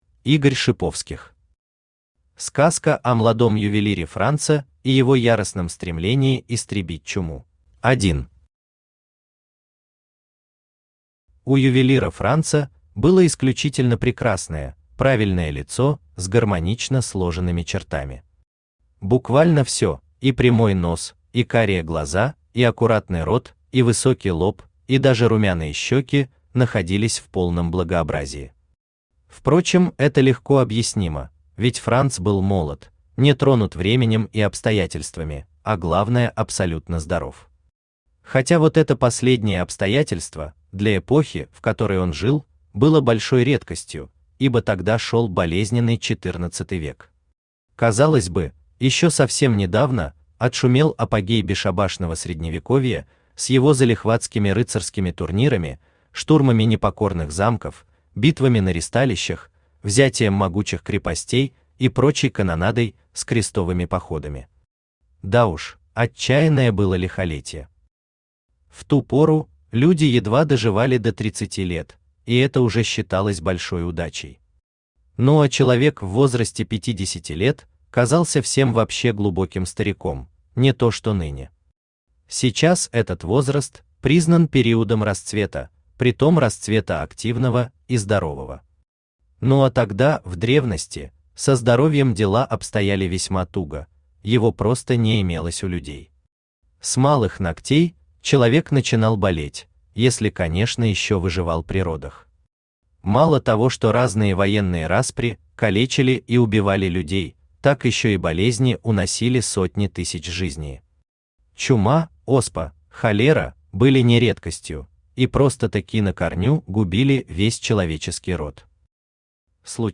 Aудиокнига Сказка о младом ювелире Франце и его яростном стремлении истребить чуму Автор Игорь Дасиевич Шиповских Читает аудиокнигу Авточтец ЛитРес.